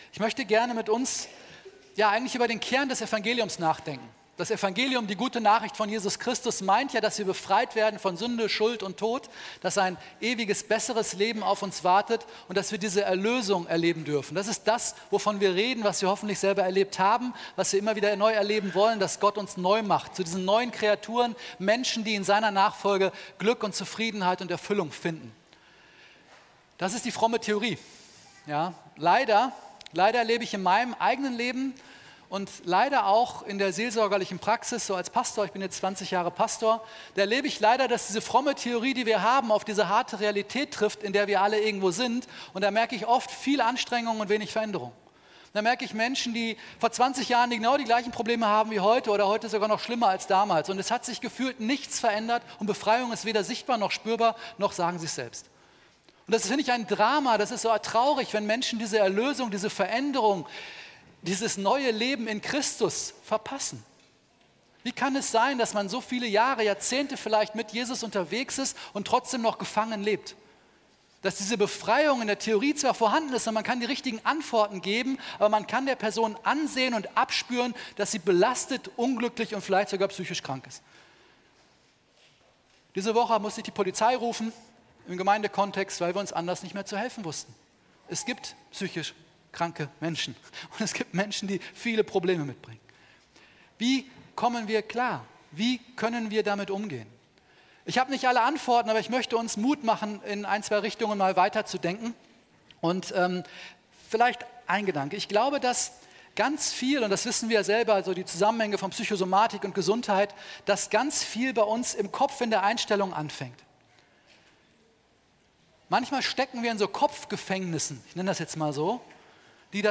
Mitschnitt vom 25.04.2026 zum Thema „Bruecken bauen"